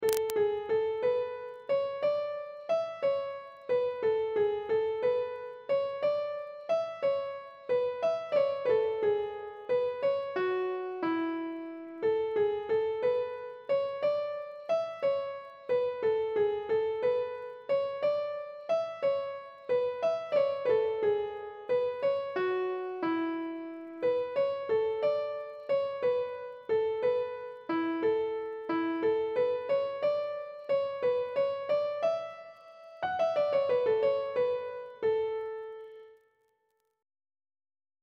Air.